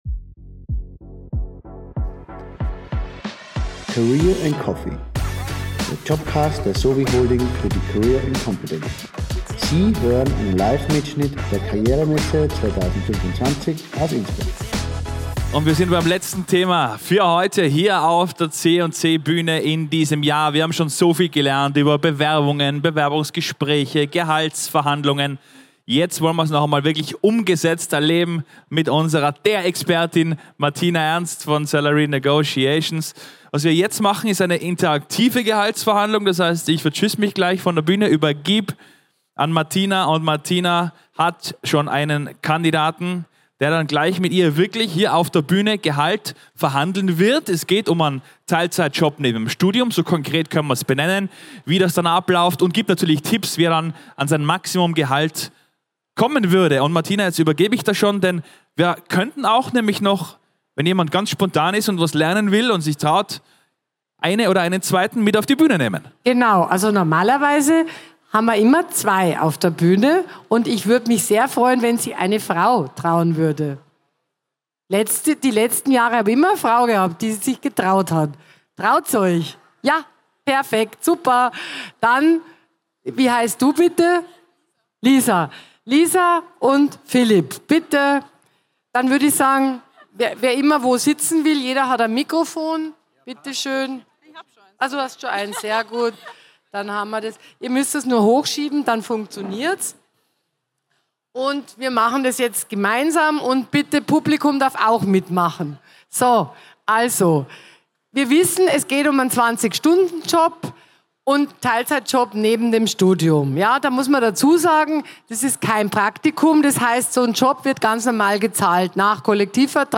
Livemitschnitt #11 von der career & competence am 14. Mai 2025 im Congress Innsbruck.
Live vor Publikum haben wir Schritt für Schritt eine Gehaltsverhandlung durchgespielt – realistisch, praxisnah und mit wertvollen Tipps & Tricks, wie du beim nächsten Gespräch selbstbewusst auftrittst und überzeugend argumentierst.